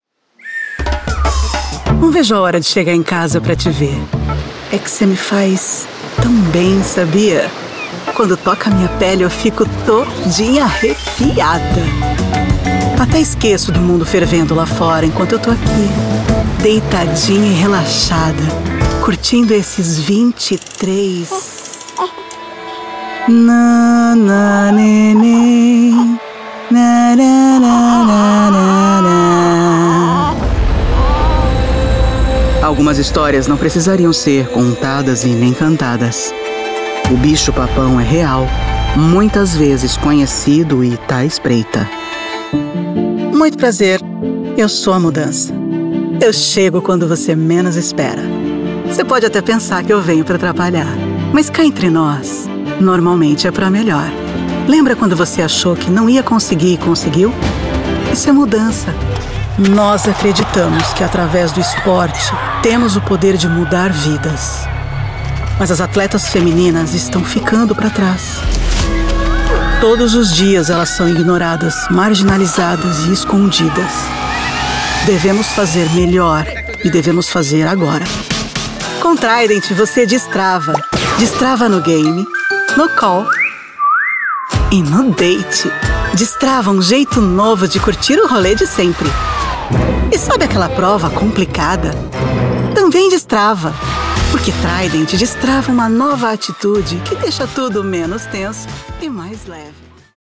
Meine Stimme ist tief, sexy und einladend. Heimstudio und schnelle Lieferung.
Sprechprobe: Werbung (Muttersprache):
My voice is deep, sexy and inviting.